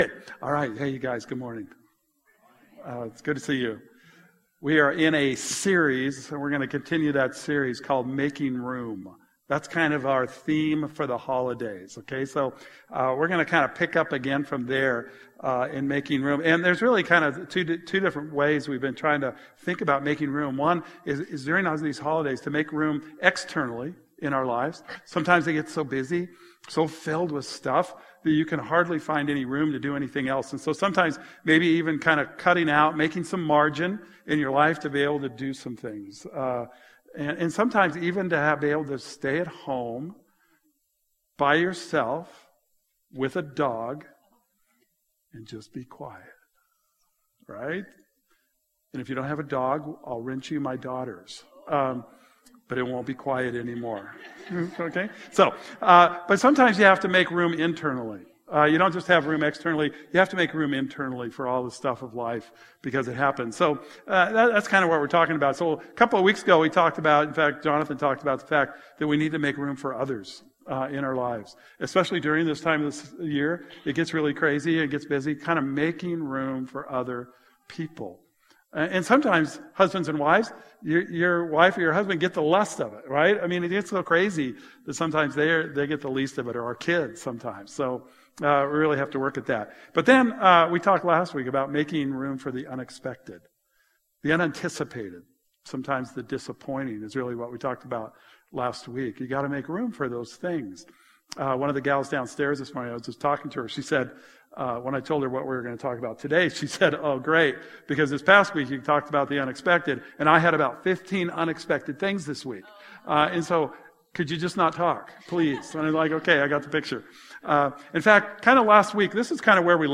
Today's message is part 4 of our Christmas series, Making Room, and talks about the importance of taking room for forgiveness... not for just today, or this Christmas, but in the Christian walk in general.